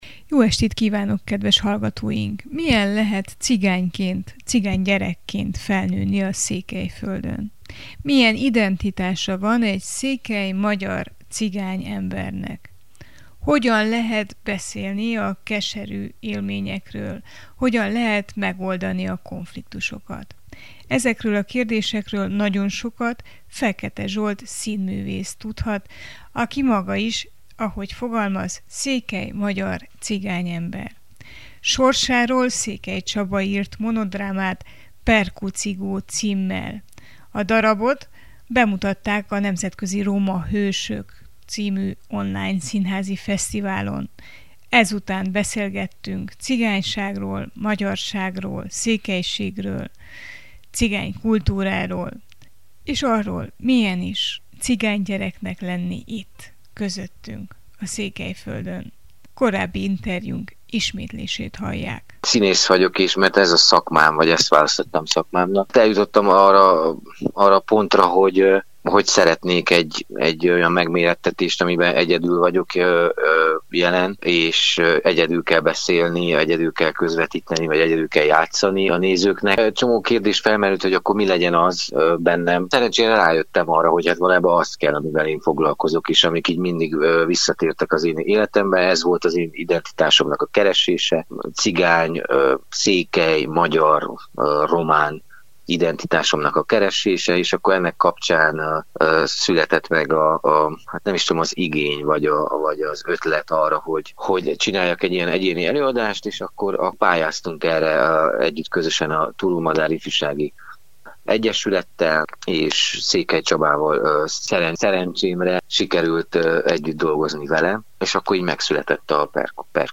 A bemutató után beszélgettünk